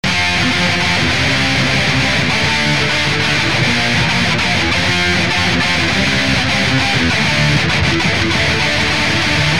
朋克风格的GTR和弦2
描述：朋克式和弦进展的第二部分
标签： 100 bpm Punk Loops Guitar Electric Loops 1.62 MB wav Key : Unknown
声道立体声